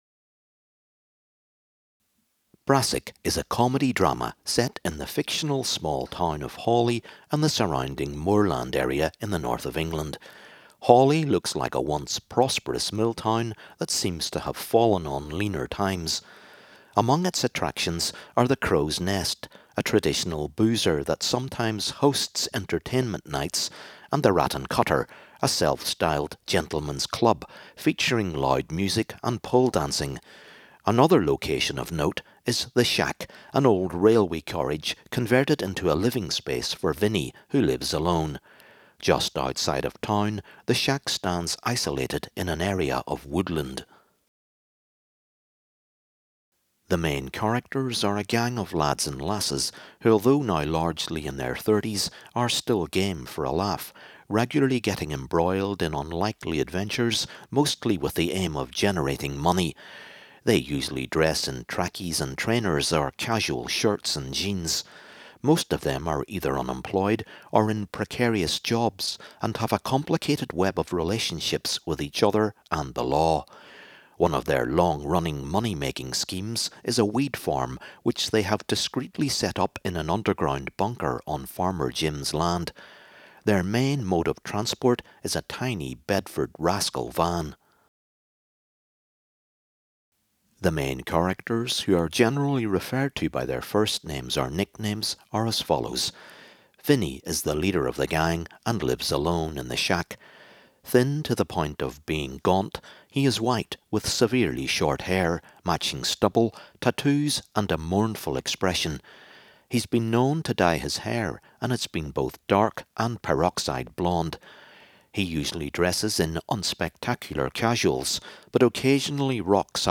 Brassic, Series 5 - AD Introduction